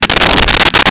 Sfx Whoosh 4803
sfx_whoosh_4803.wav